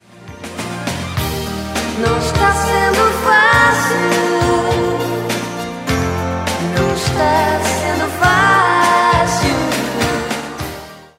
Trecho da música
cantora